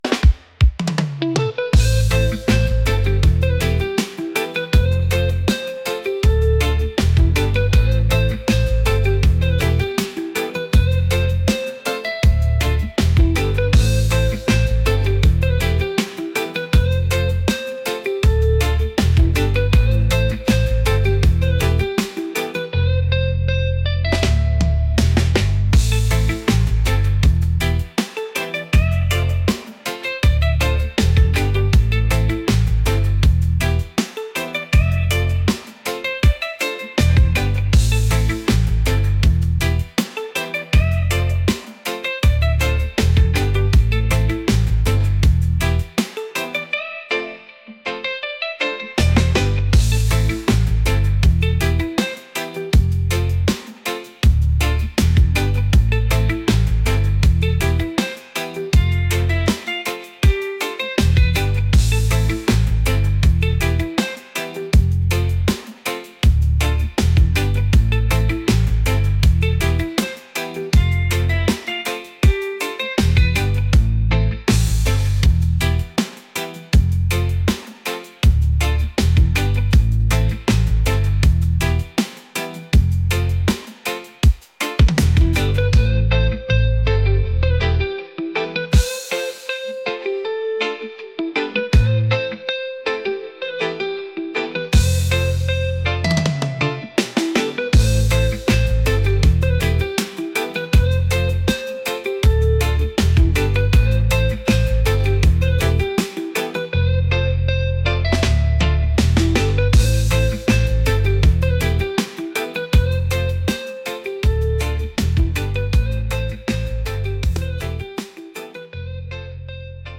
reggae | groovy